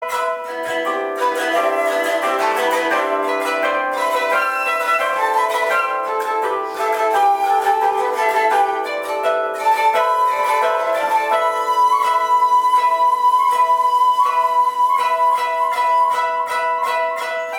ここは曲中いちばんリズミカルなところですので尺八にはないタンギング奏法で吹きましょう。